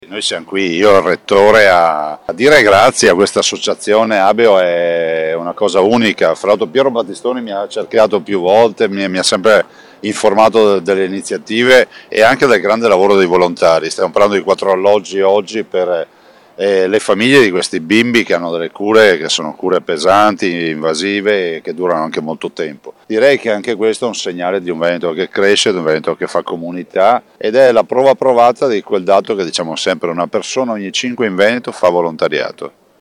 Presidente-Veneto-Luca-Zaia-alla-cerimonia-Abeo-inizio-lavori-per-realizzazione-4-appartamenti.mp3